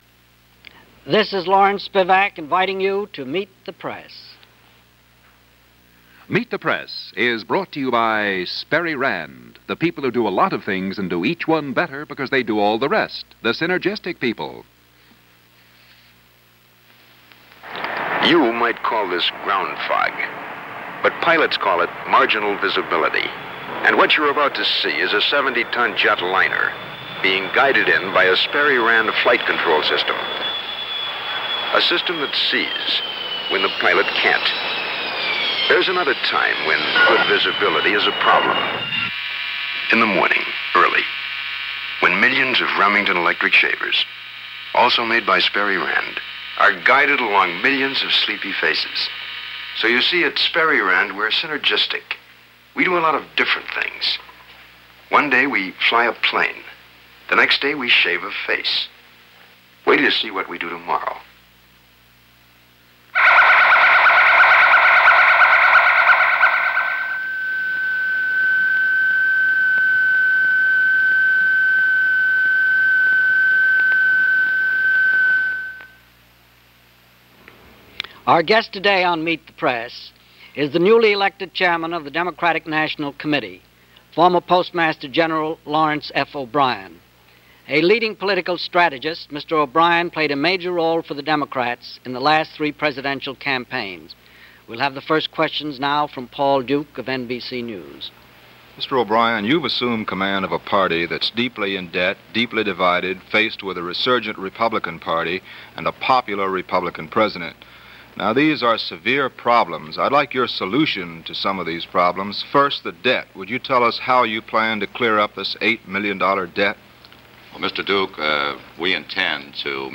Lawrence O'Brien is interviewed on Meet The Press- March 15, 1970 - discussing changes taking place in the Democratic party ahead of 1970 mid-term elections.